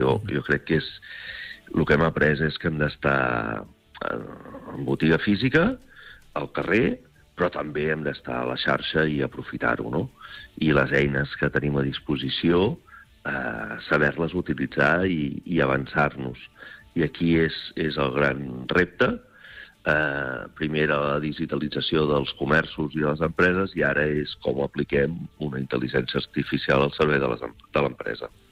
ha fet balanç del 2025 en una entrevista al Supermatí